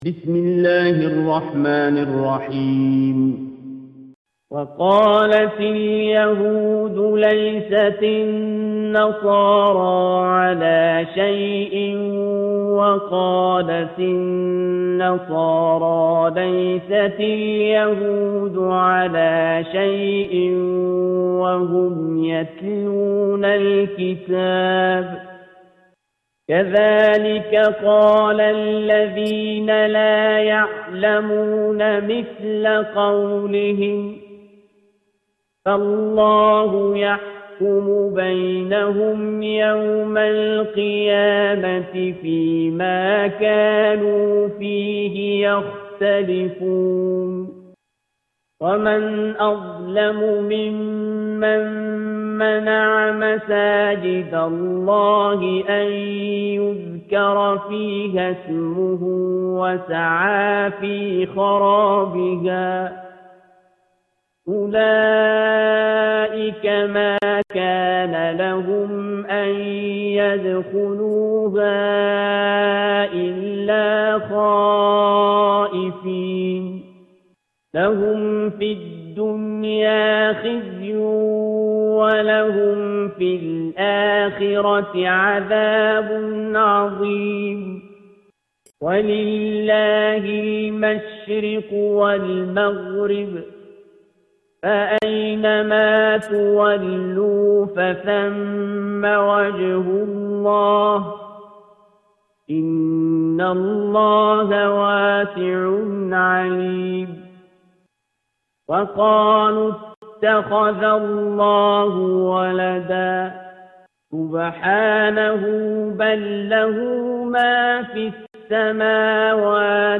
Surah Al-Baqarah - A Lecture of Tafseer ul Quran Al-Bayan by Javed Ahmed Ghamidi.